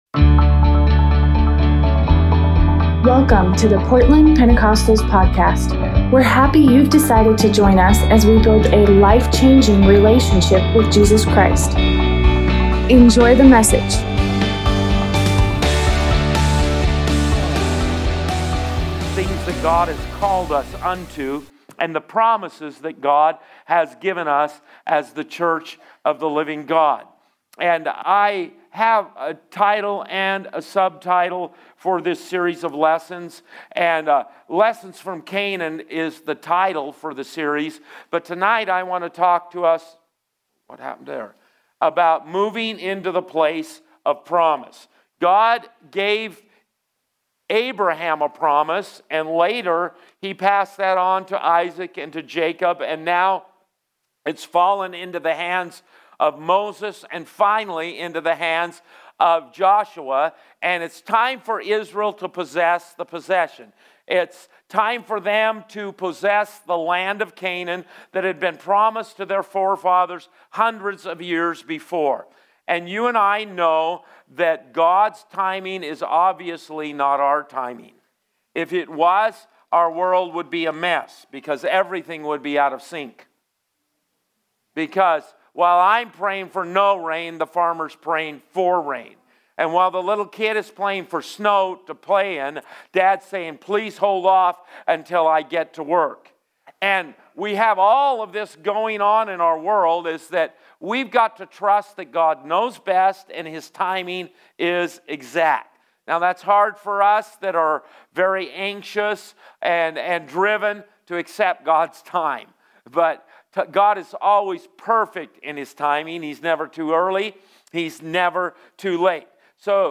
Tuesday Bible study